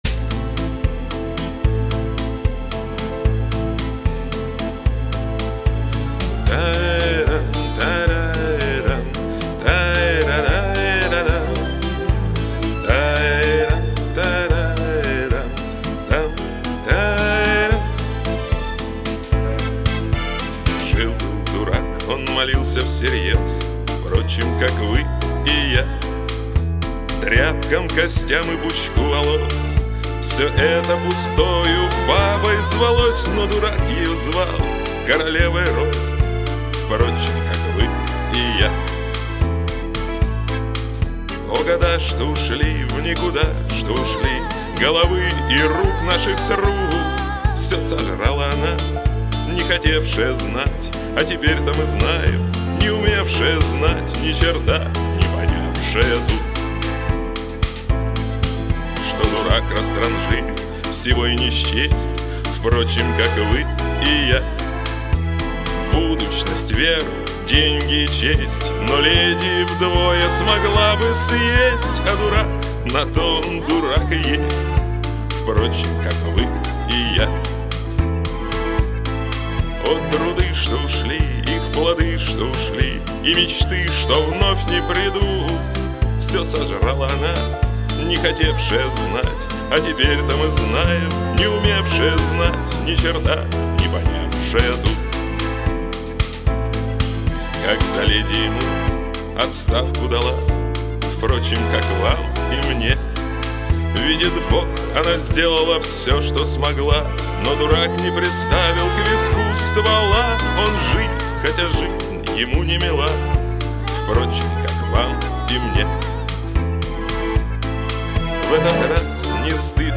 Бардрок (4123)